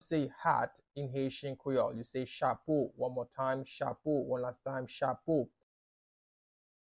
Pronunciation:
How-to-say-Hat-in-Haitian-Creole-Chapo-pronunciation-by-a-Haitian-Creole-teacher.mp3